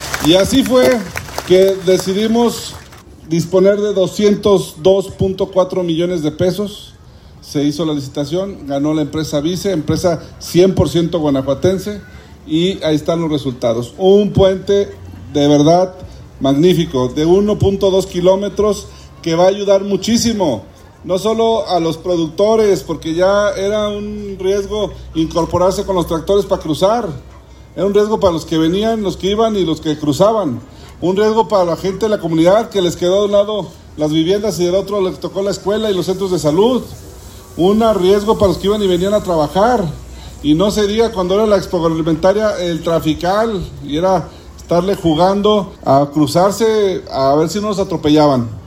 Lorena ALfaro García, presidenta